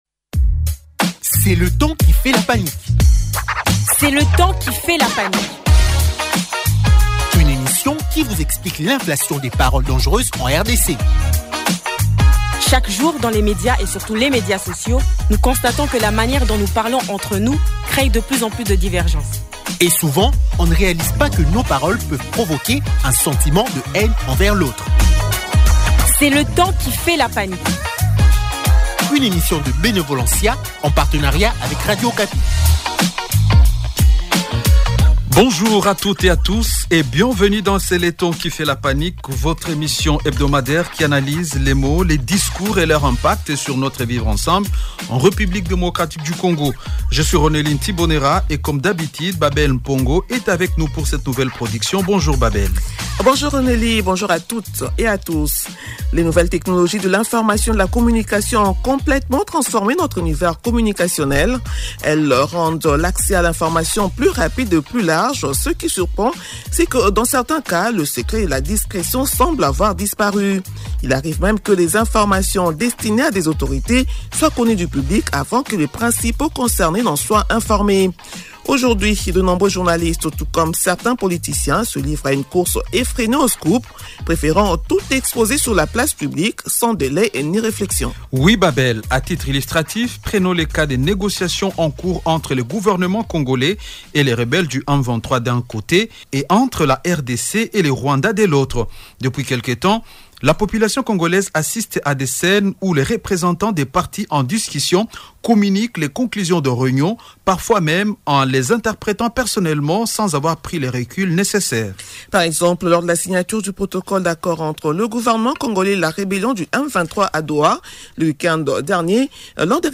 Pour en discuter, deux invités sont avec nous en studio.